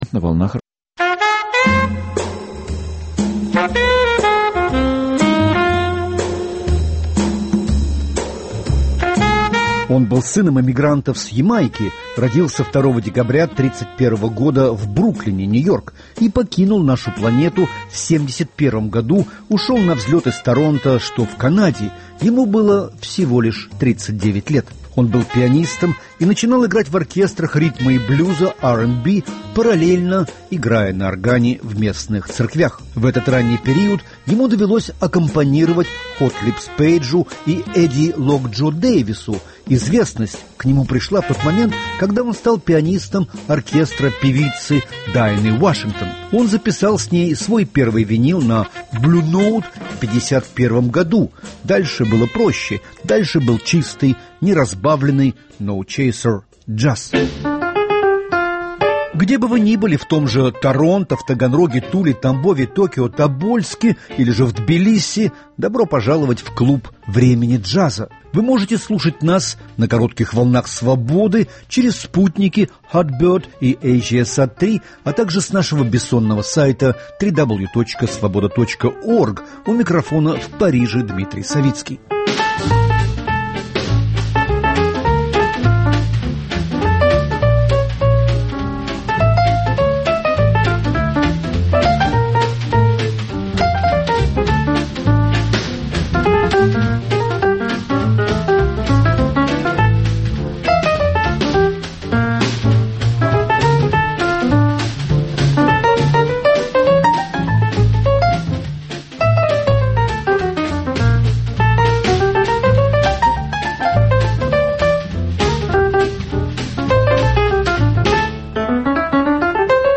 Кто все же смог уместиться в 248 выпуске джаз-шоу «Свободы».